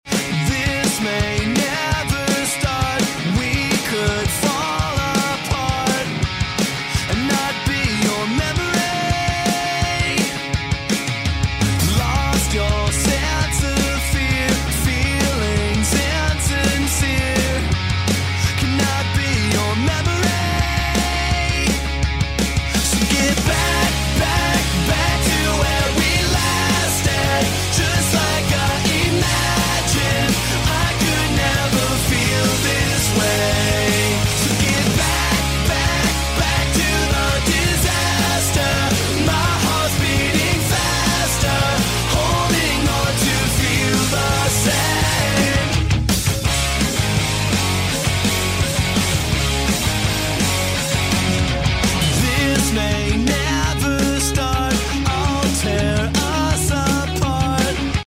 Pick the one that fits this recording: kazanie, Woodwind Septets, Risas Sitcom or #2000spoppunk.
#2000spoppunk